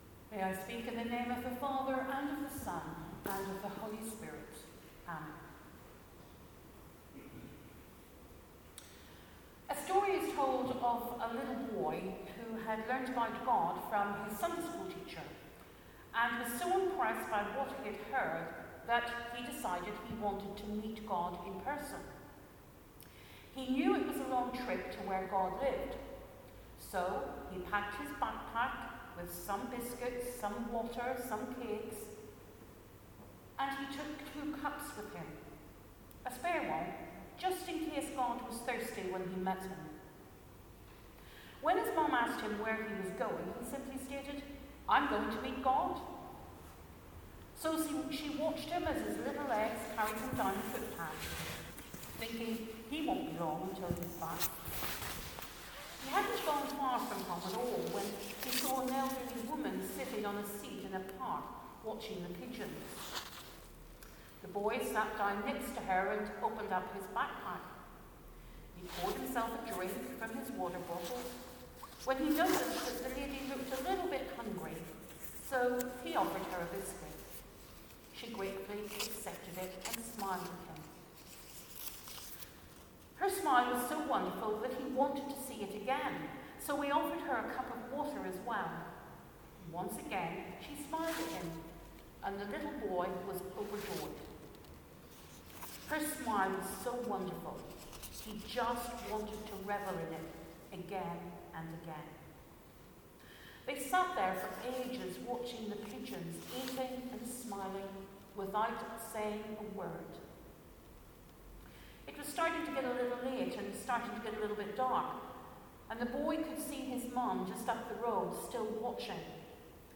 Sermon: Christ the King | St Paul + St Stephen Gloucester